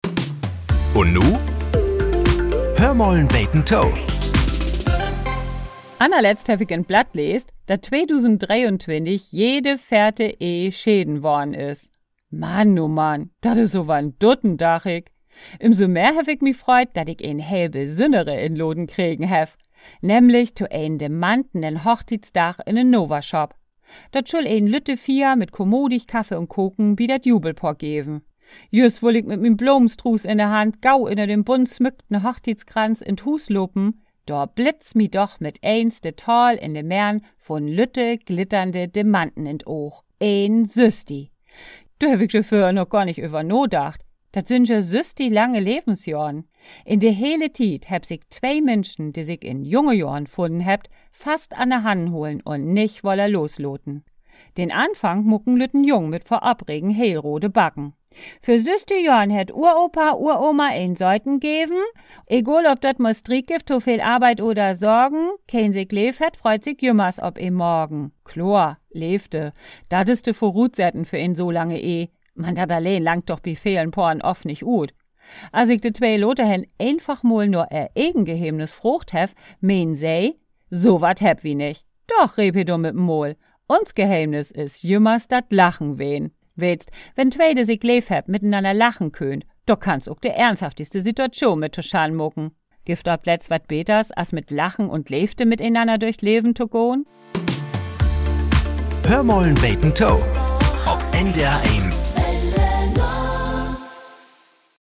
Die plattdeutsche Morgenplauderei "Hör mal 'n beten to" gehört seit mehr als 60 Jahren zum Alltag in Norddeutschland. Hier werden die Wunderlichkeiten des Alltags betrachtet. So klingt es, wenn wir Norddeutschen uns selbst auf die Schippe nehmen - liebevoll bis spöttisch, selten mit dem Finger in schmerzenden Wunden, aber immer an Stellen, an denen wir kitzelig sind.